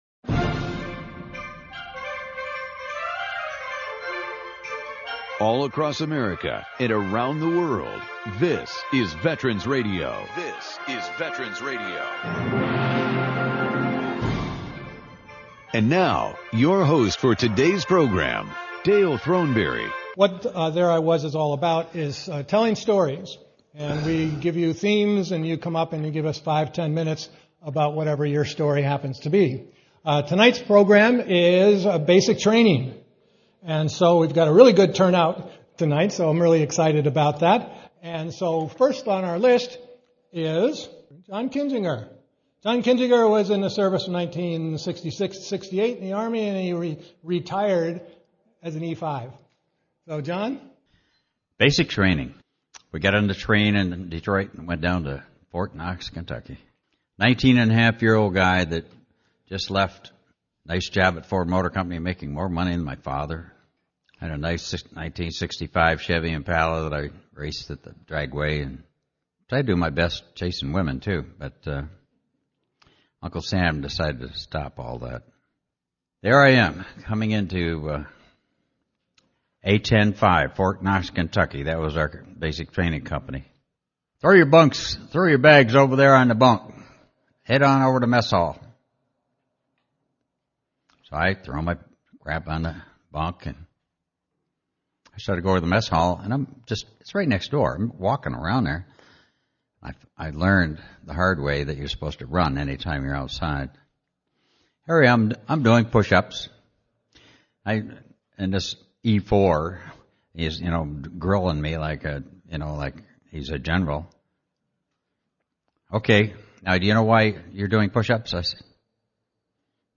"There I Was ..." Open Mic for veterans to tell their stories about their service in the U.S. military. This episode tells many stories of basic training.